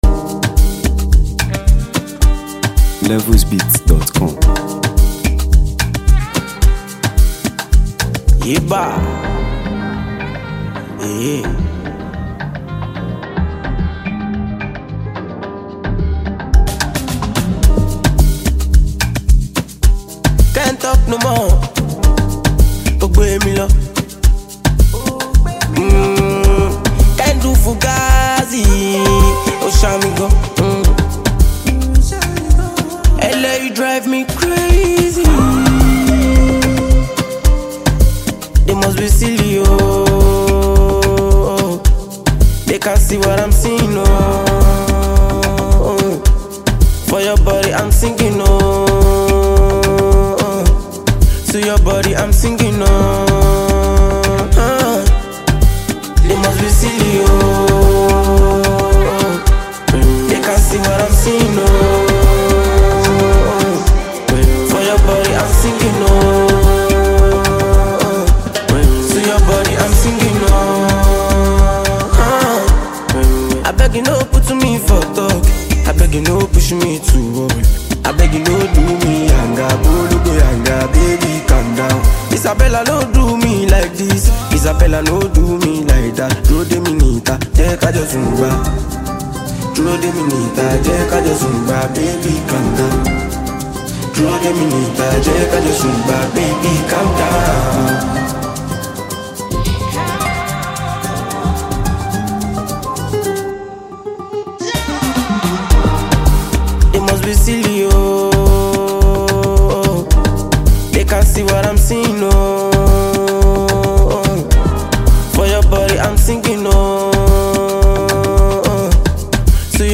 With its vibrant beats and catchy melodies